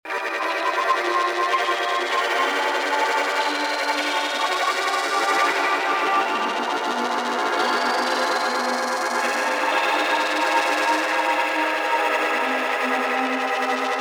SynthesizerVの声をエクスポートする方法
NoiseKarin.mp3